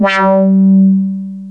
WAH 2.wav